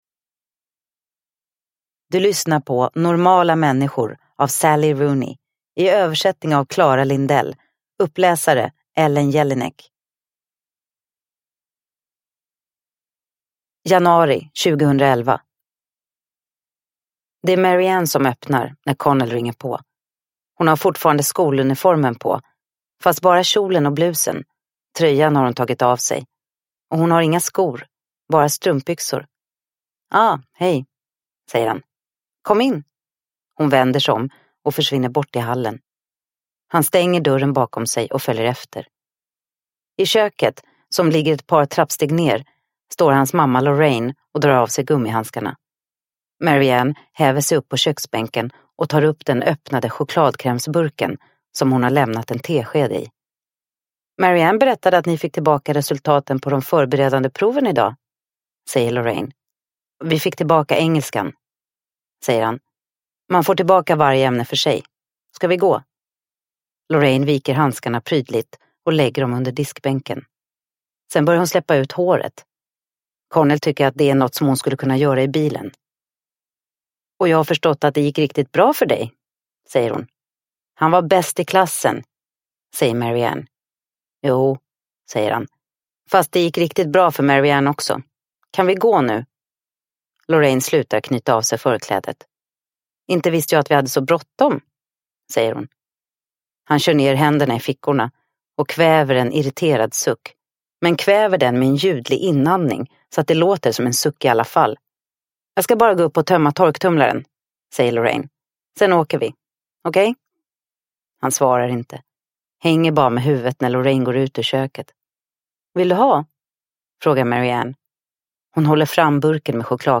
Normala människor – Ljudbok – Laddas ner